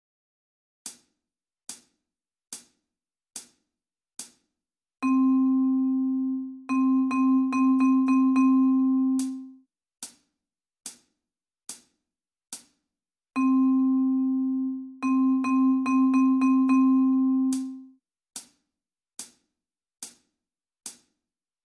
lecture rythmique A1